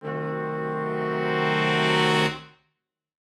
Index of /musicradar/gangster-sting-samples/Chord Hits/Horn Swells
GS_HornSwell-Cdim.wav